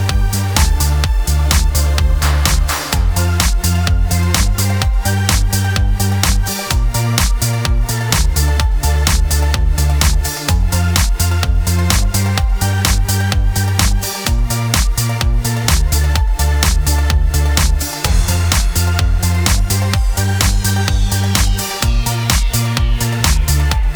Minus All Guitars Pop (2000s) 3:22 Buy £1.50